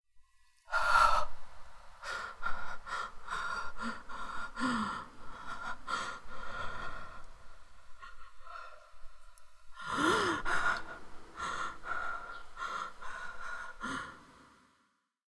scared-breathing.wav